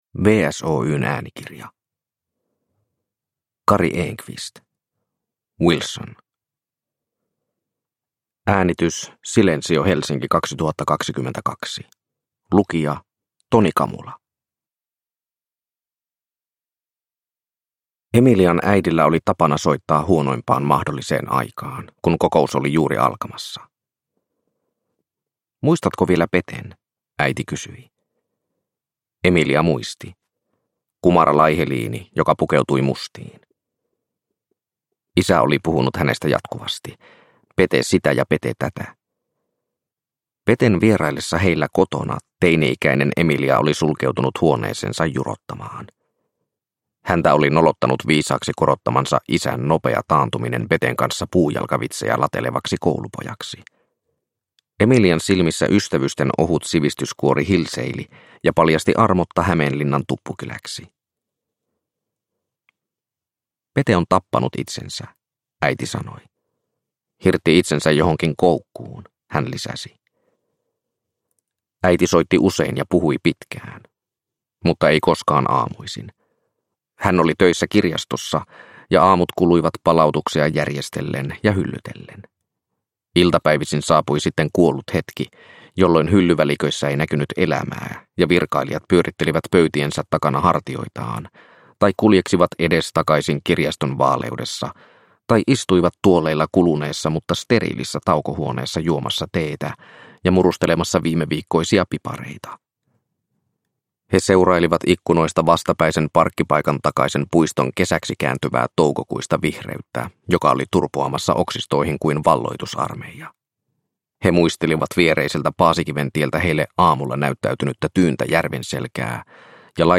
Wilson – Ljudbok